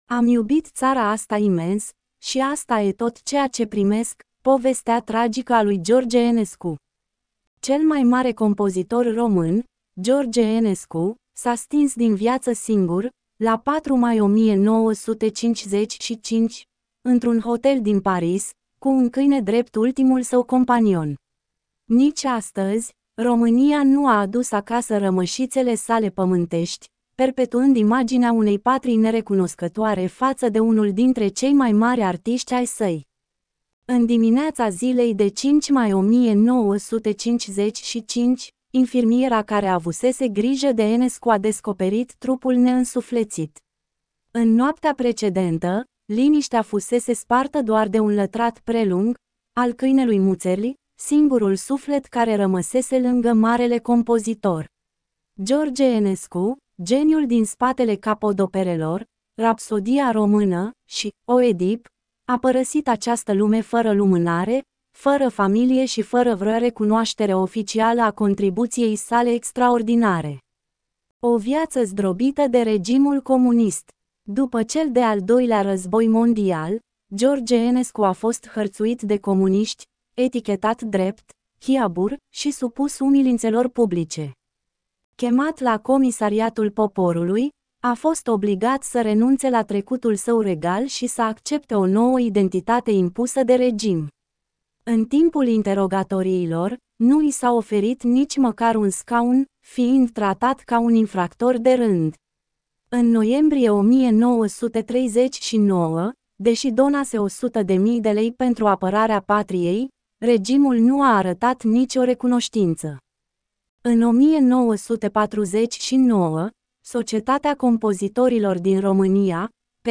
Get in touch with us Ascultă articolul Cel mai mare compozitor român, George Enescu, s-a stins din viață singur, la 4 mai 1955, într-un hotel din Paris, cu un câine drept ultimul său companion.